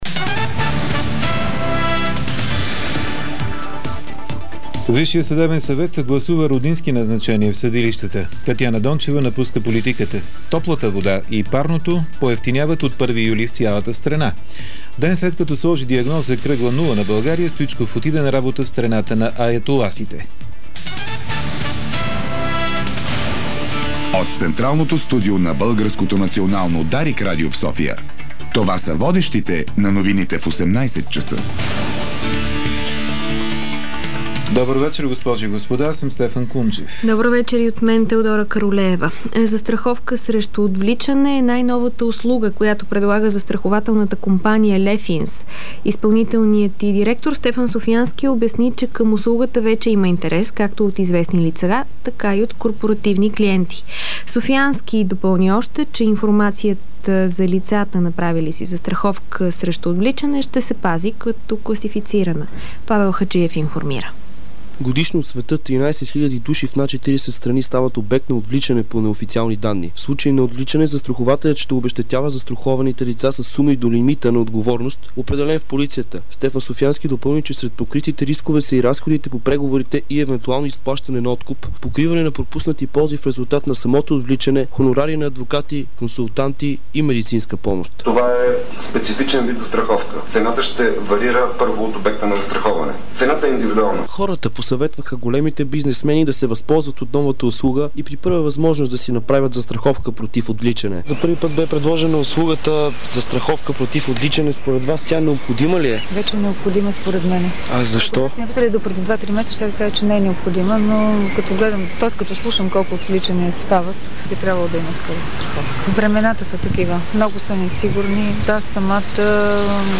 Обзорна информационна емисия - 13.05.2009